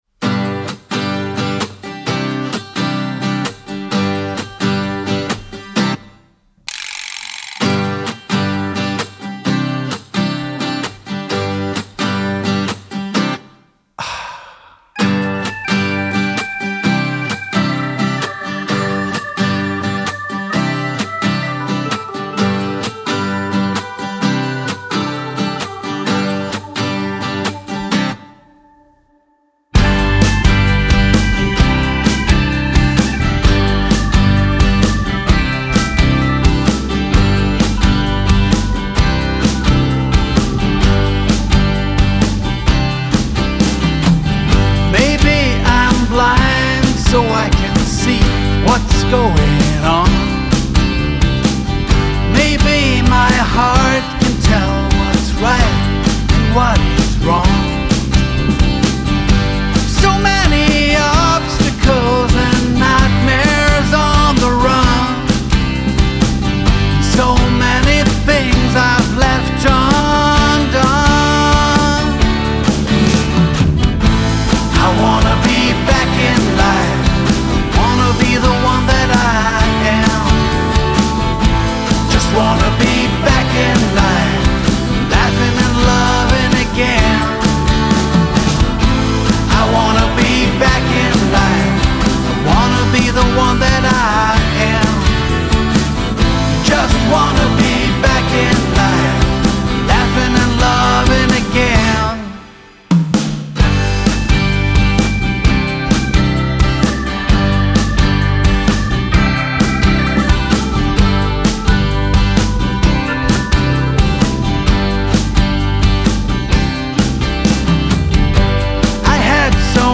Mai 2024, ist eine Rock-EP mit drei Songs.